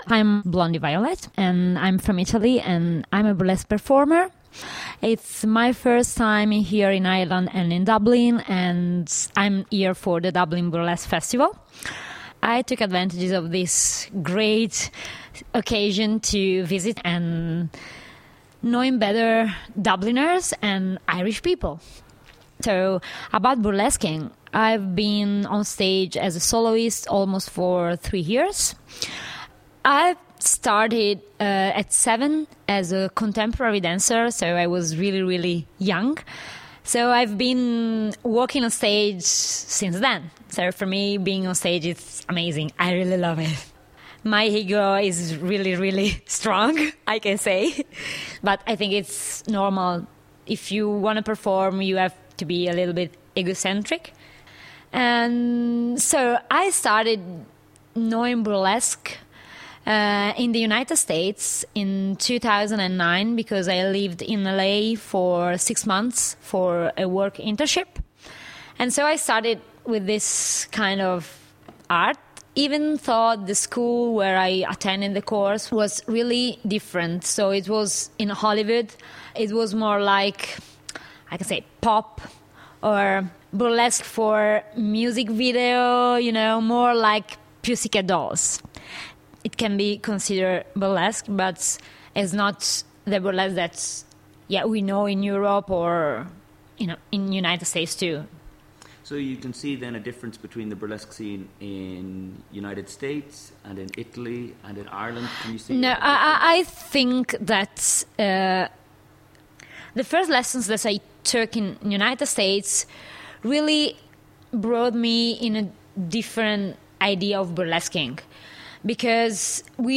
L’interno documentario “Burly Dublin” sarà trasmesso su 103.2 Dublin City FM , Martedì 10 Novembre alle 12:30 (GMT).